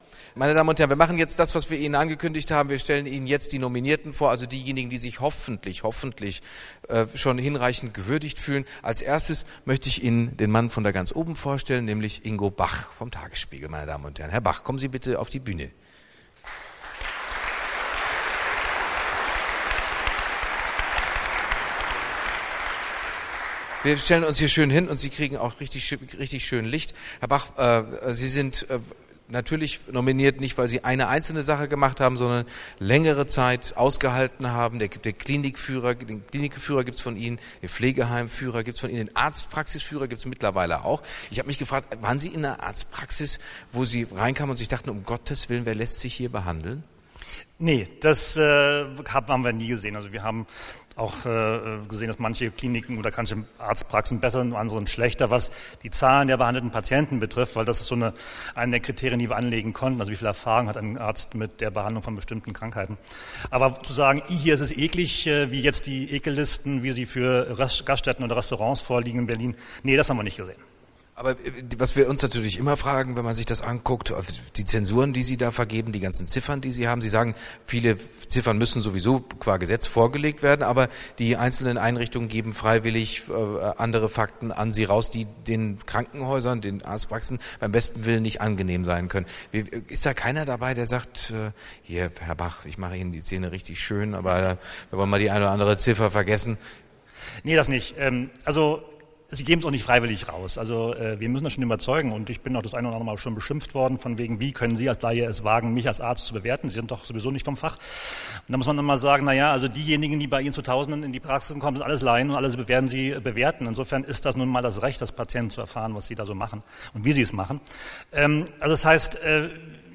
Wer: Sabine Leutheusser-Schnarrenberger, MdB, Bundesministerin der Justiz
Was: Rede als Ehrengast bei der Preisverleihung „Der lange Atem“ 2010
Wo: Berlin, Radialsystem